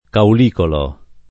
[ kaul & kolo ]